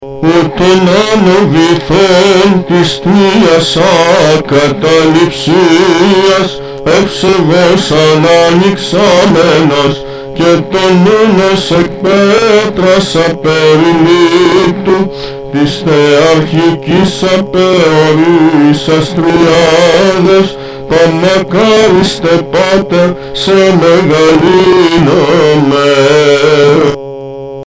Ὁ Εἱρμὸς